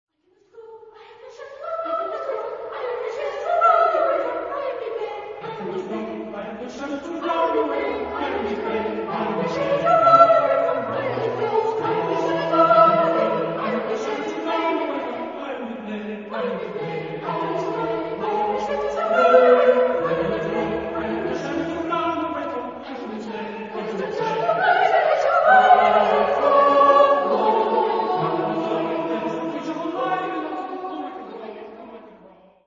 Genre-Style-Forme : Sacré ; Hymne (sacré)
Type de choeur : SSATB  (5 voix mixtes )